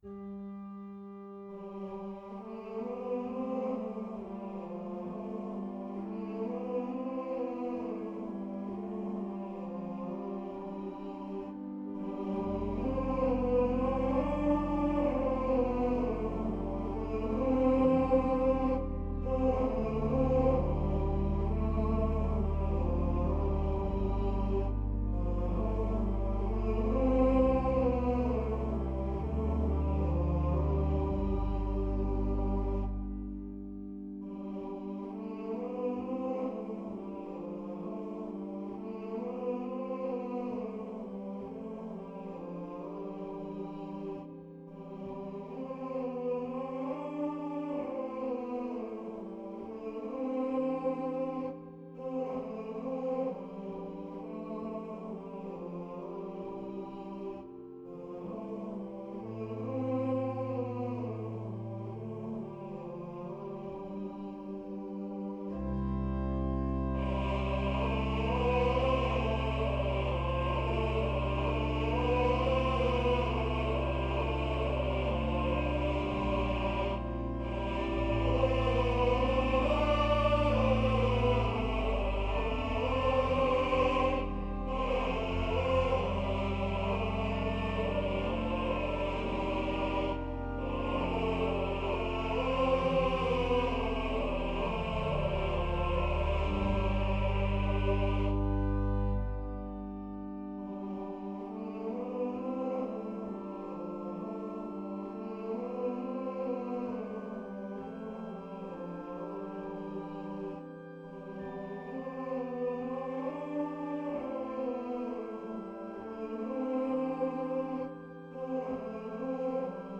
Office hymn harmonisations
Since it has been the custom to sing Gregorian office hymns in the Choral Evensongs at the Dominicanenklooster (Dominican Convent) in Zwolle, I have composed an organ accompaniment each time.